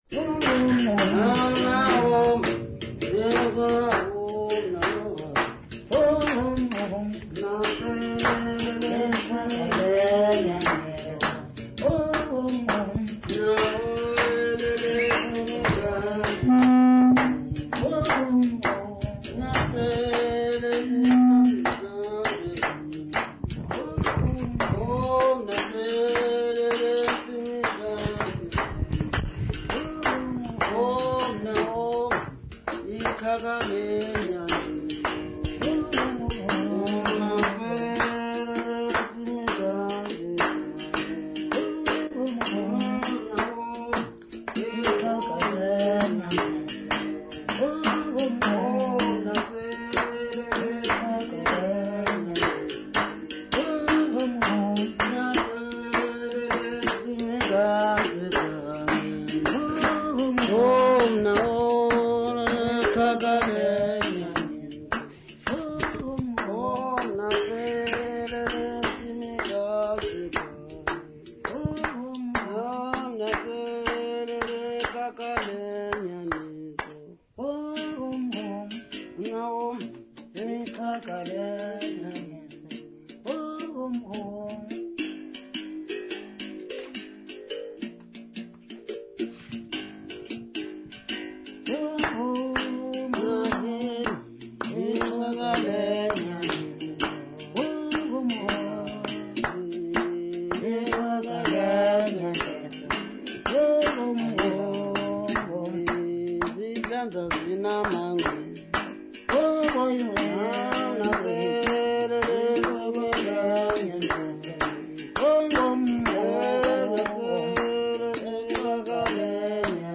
Sacred music South Africa
Folk music South Africa
Hymns, Xhosa South Africa
Africa South Africa McKay's Neck, Eastern Cape sa
field recordings
Ntsikana hymn with Uhadi accompaniment.